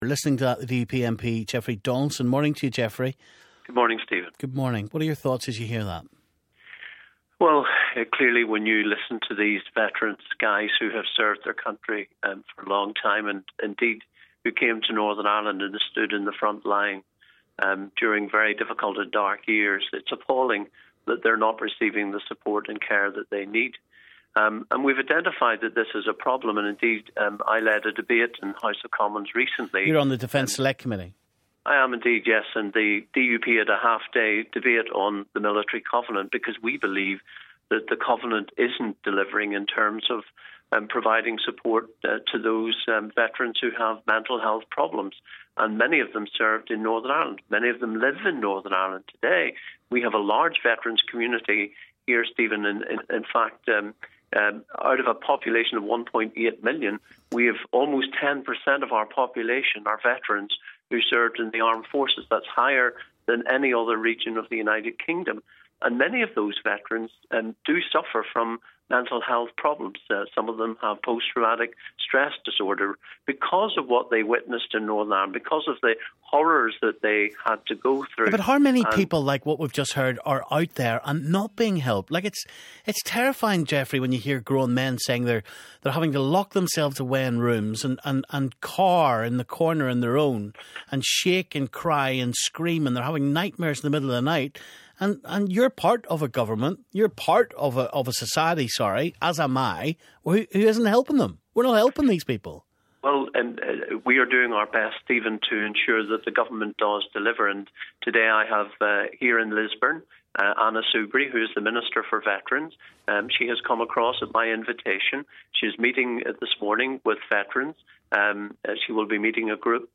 Stephen talks to DUP MP Jeffrey Donaldson